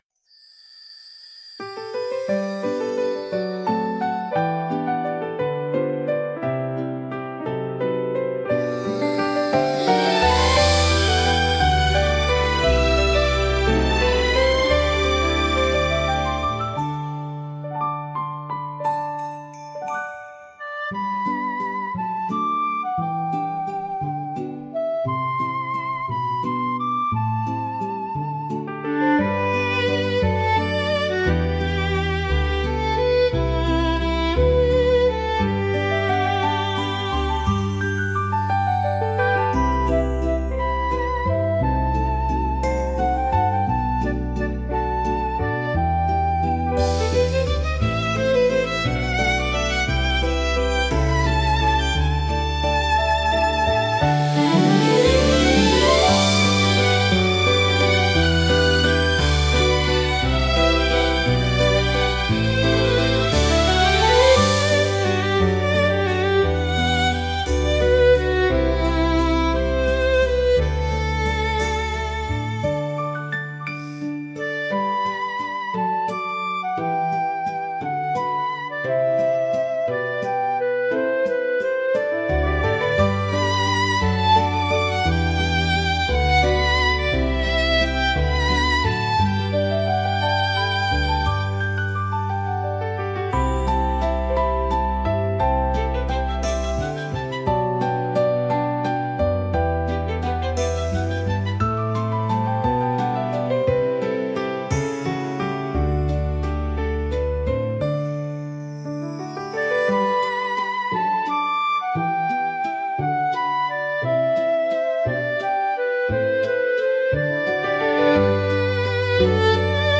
明るさと元気さで、場の雰囲気を一気に盛り上げ、聴く人にポジティブな感情を喚起します。